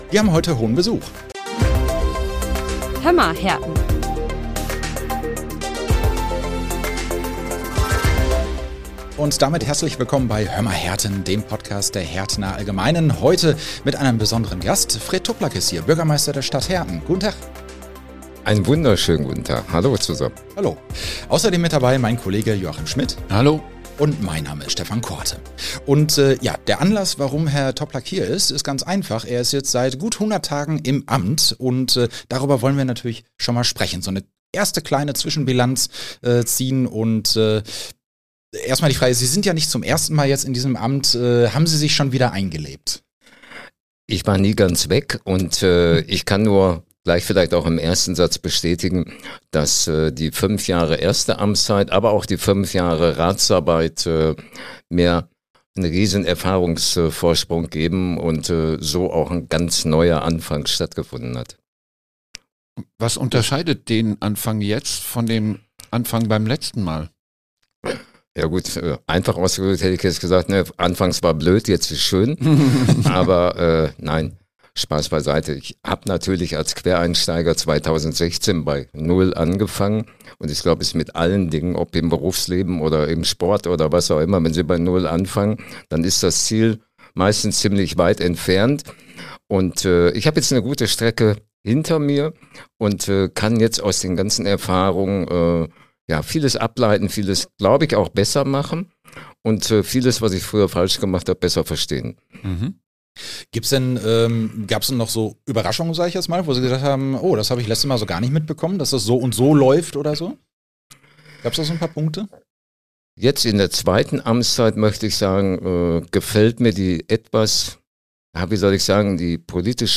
Heute ist Hertens Bürgermeister Fred Toplak zu Gast in unserem Podcast.